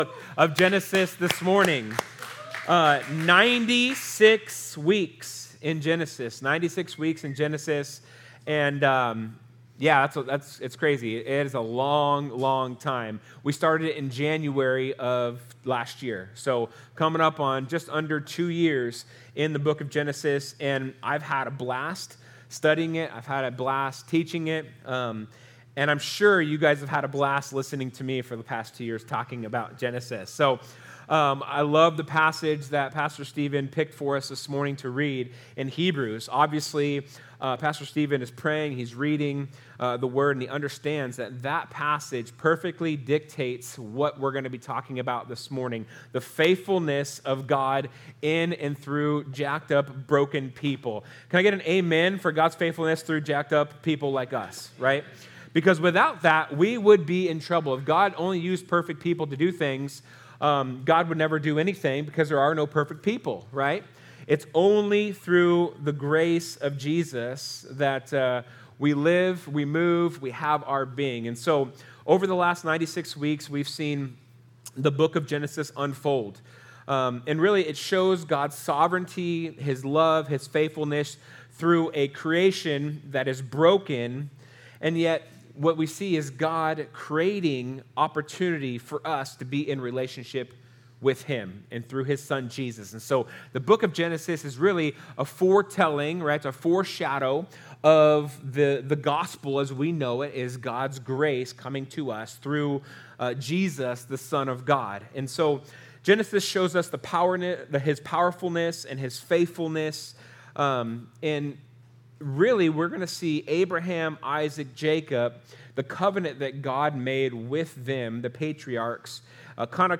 Sermons | The Table Fellowship